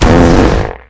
pl_impact_airblast3.wav